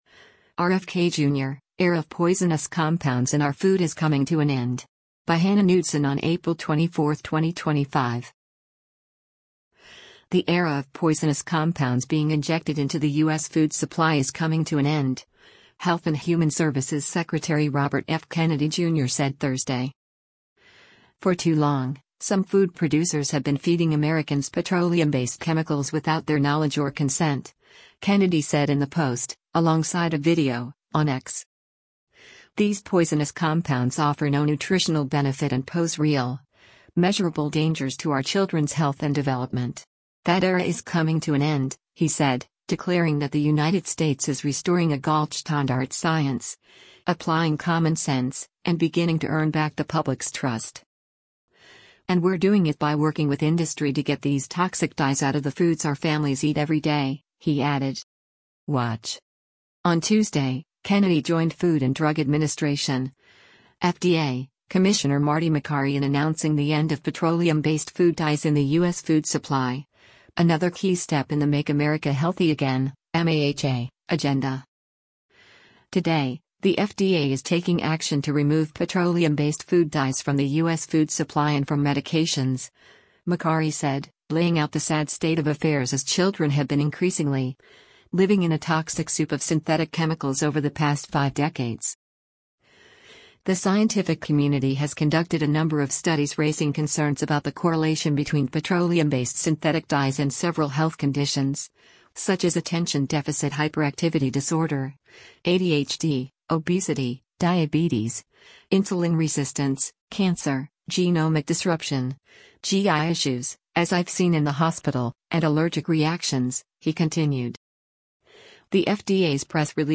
Health and Human Services Secretary Robert F. Kennedy Jr. speaks at a news conference on r